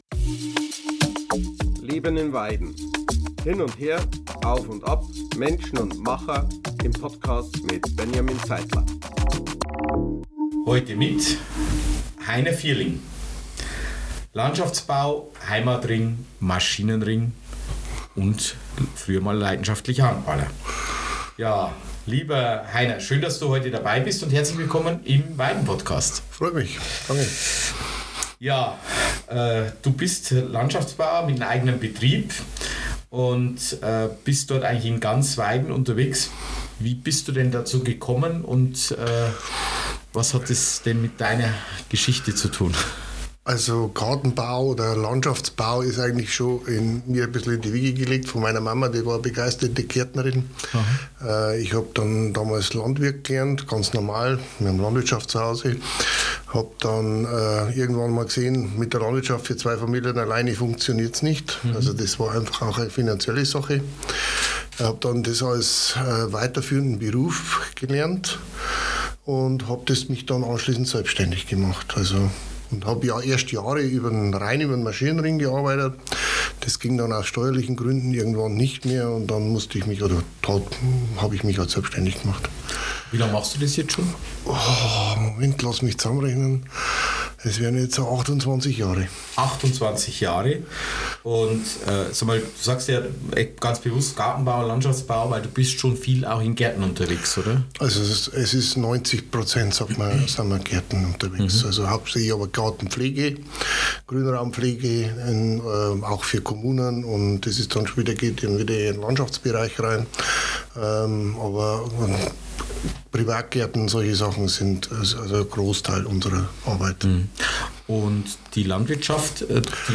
Ein Gespräch mit einem, der zupackt, Verantwortung übernimmt und in Beruf, Ehrenamt und Sport fest in Weiden verwurzelt ist.